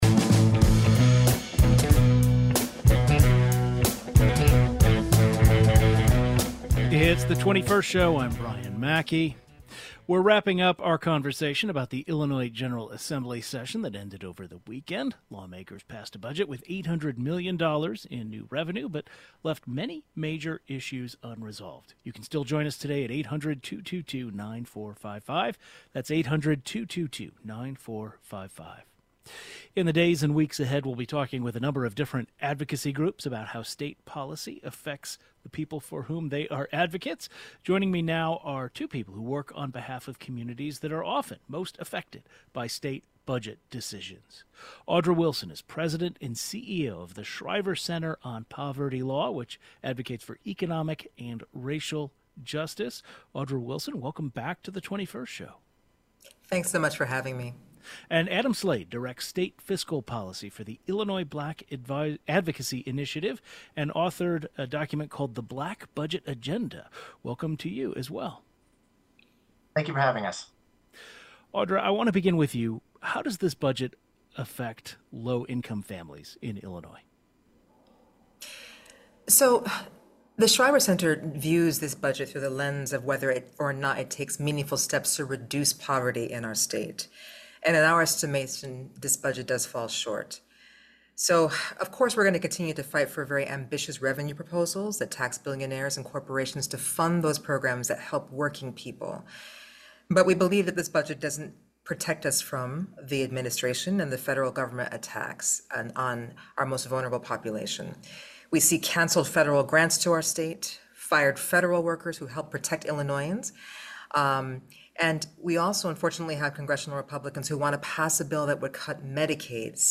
Lawmakers passed a budget with $800 million in new revenue, but left many major issues unresolved. Does this budget address issues faced by low-income families, immigrants, and Black Illinoisans? Two advocates who work on behalf of communities that are often most affected by state budget decisions give their take.